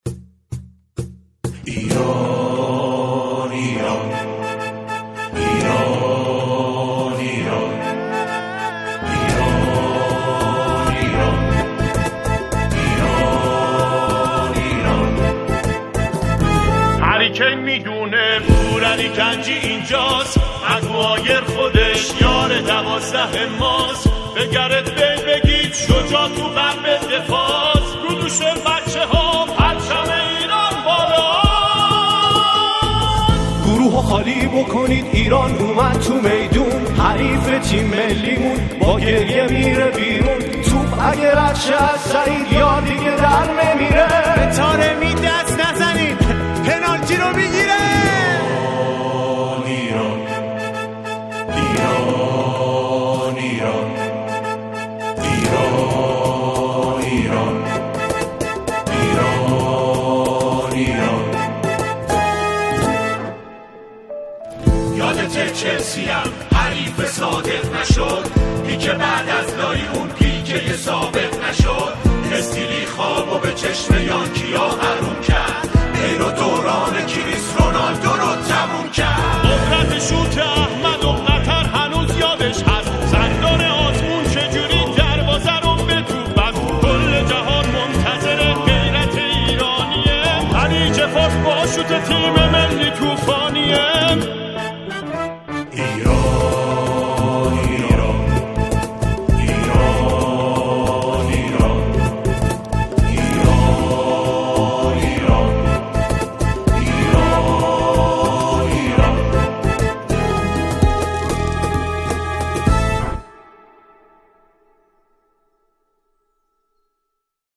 سرودهای ورزشی
آنها در این قطعه، شعری ورزشی را همخوانی می‌کنند.